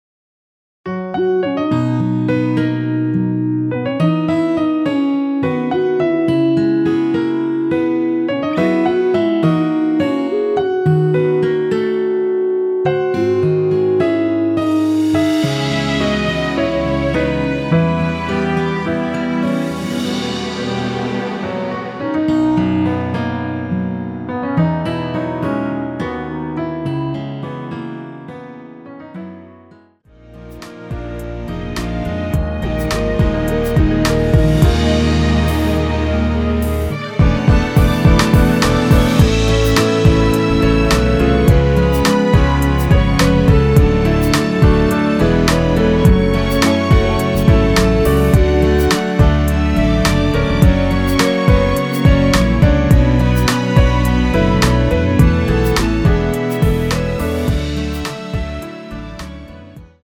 원키에서(-4) 내린 멜로디 포함된 MR 입니다.(미리듣기 참조)
앞부분30초, 뒷부분30초씩 편집해서 올려 드리고 있습니다.
중간에 음이 끈어지고 다시 나오는 이유는
(멜로디 MR)은 가이드 멜로디가 포함된 MR 입니다.